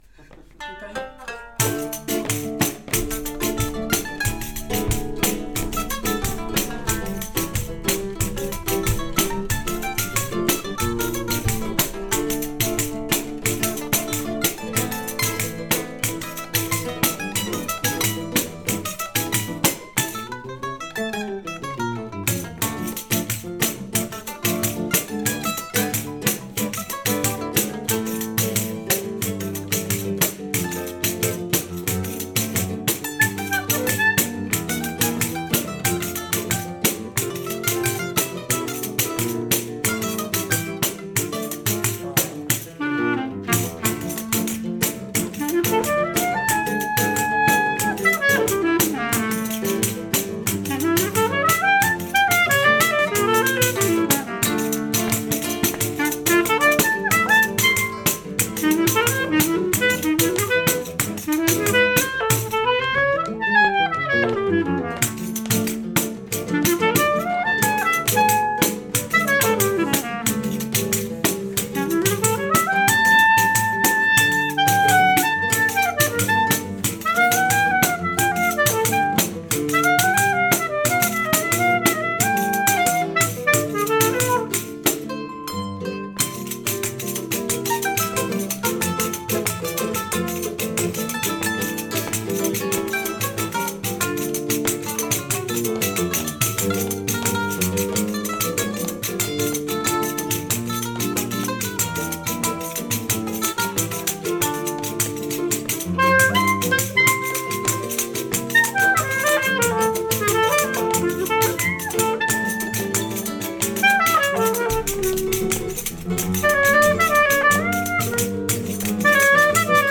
Som Espacial Présentation d'un Enregistrement Audio Télécharger Contact Email Saxofone, Porquê Choras ? Dernier changement de fichier le : 21/11/2023 Tags : |Rec atelier| Eléments associés à cet Enregistrement : Description : Répétition novembre 2023 Envoyez